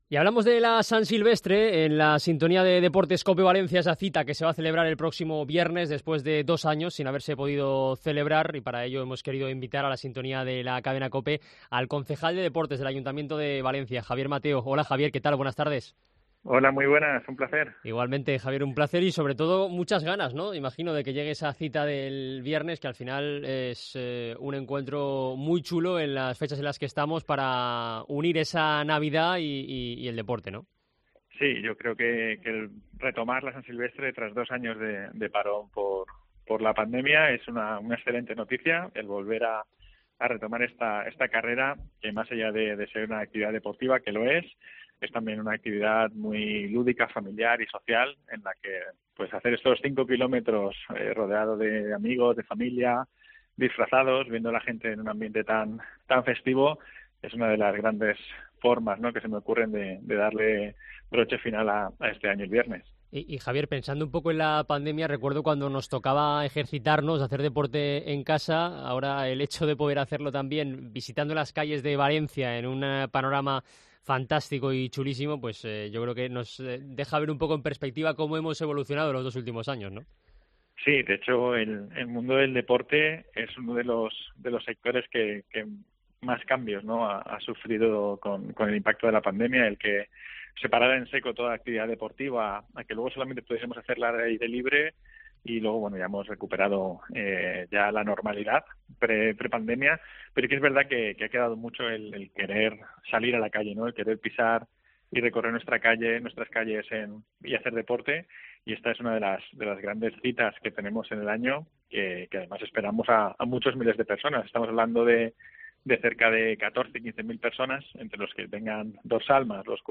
La tradicional prueba navideña celebra su 37º edición tras el parón por la pandemia. El concejal de deportes, Javier Mateo, habla en COPE sobre la cita